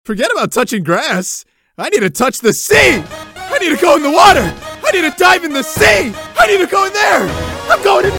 You Just Search Sound Effects And Download. tiktok laughing sound effects Download Sound Effect Home